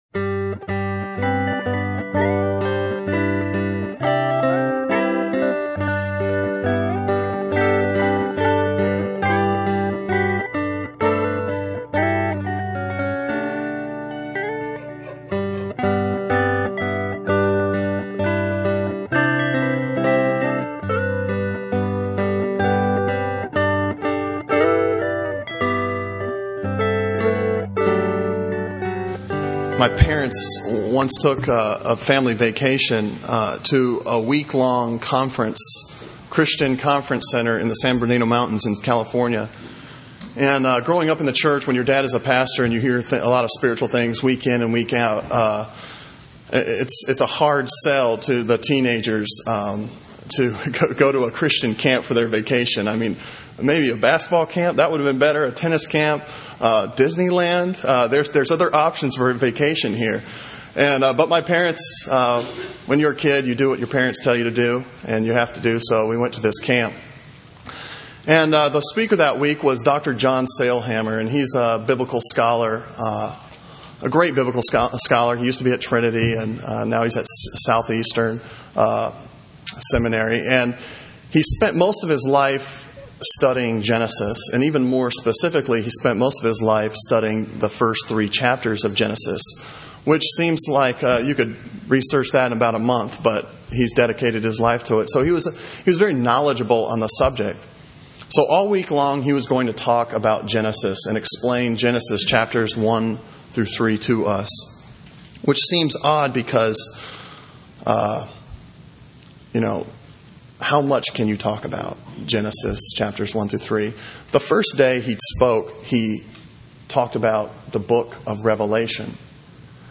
Sermon on Philippins 1:3-11 from September 10